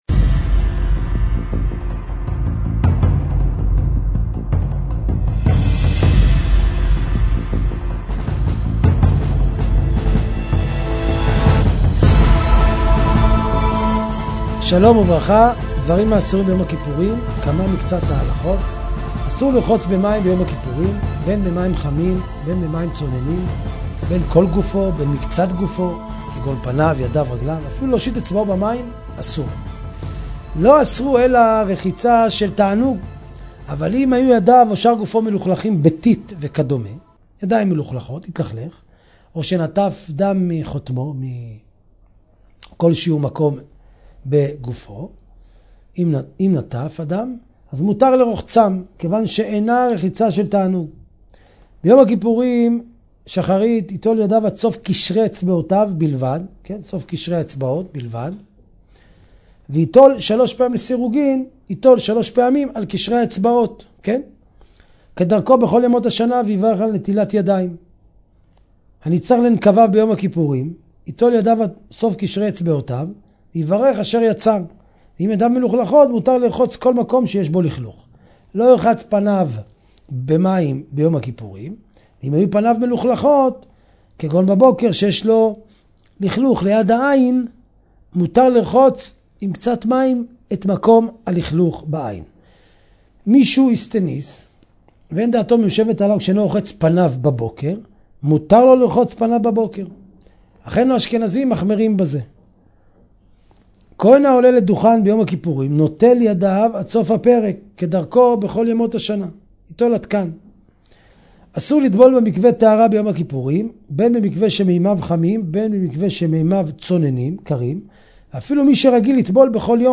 torah lesson